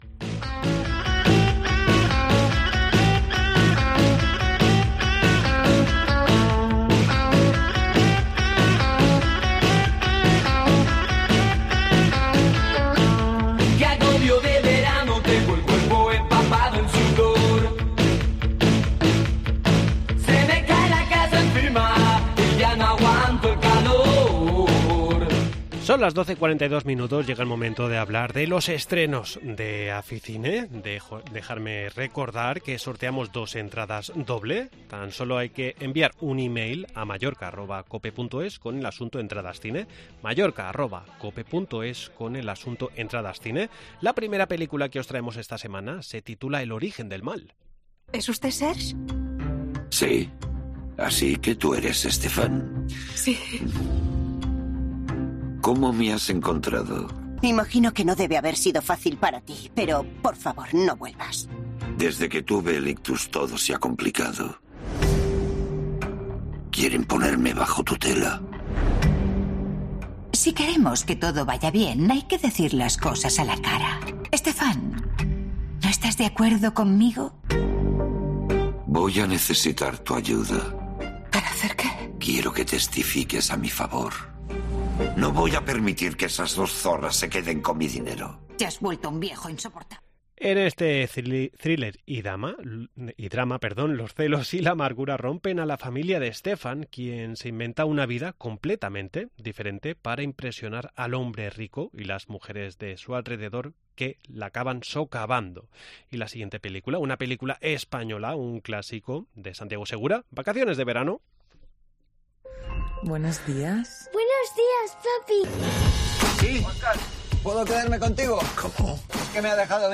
. Entrevista en La Mañana en COPE Más Mallorca, viernes 07 julio de 2023.